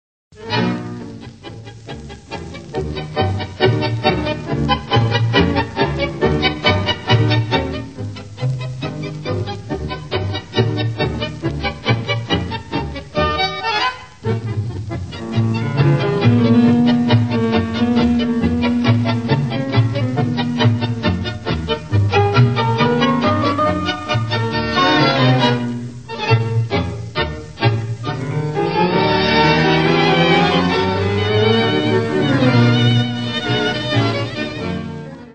Klassische Tangos